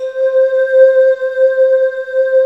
Index of /90_sSampleCDs/USB Soundscan vol.28 - Choir Acoustic & Synth [AKAI] 1CD/Partition D/19-IDVOX FLT